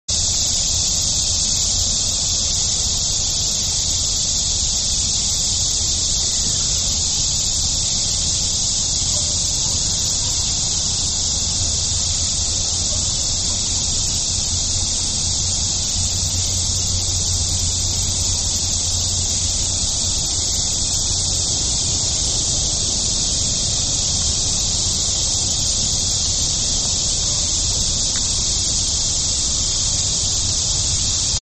This insect calls during the day.
These were in our yard.
You can also hear some other insects in the background.
Cicadas-Aug-22011SBendearlyev.mp3